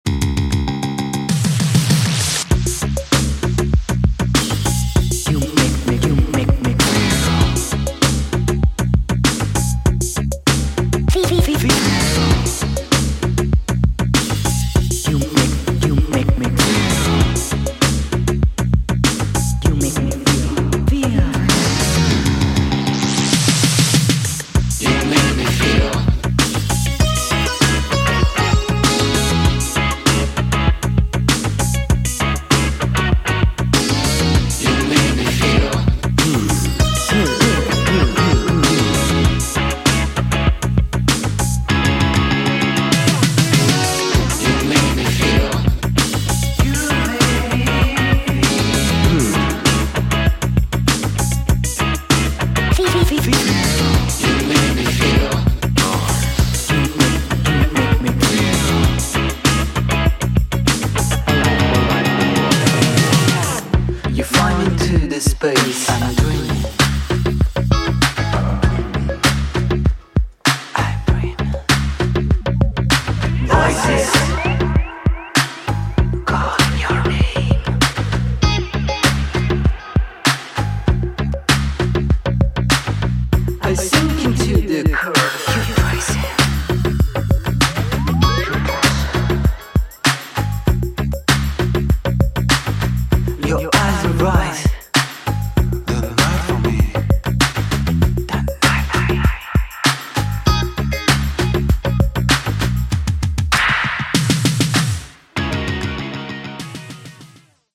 he presents a nostalgic vision of twilight synth-pop